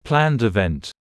7.planned event / plænd ɪˈvɛnt / (n.phr): sự kiện đã được lên kế hoạch